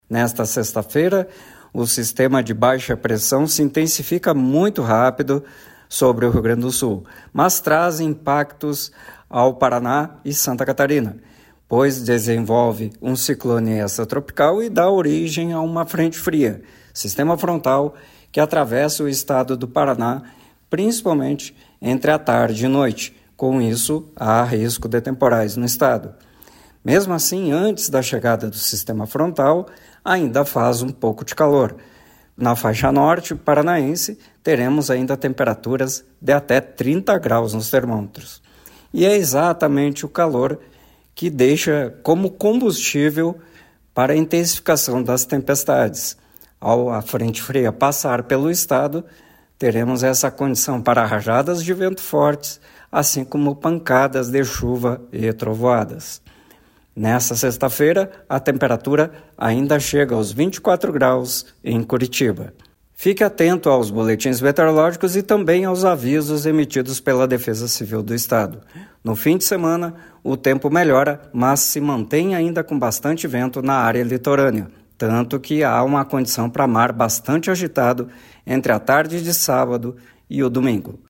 O meteorologista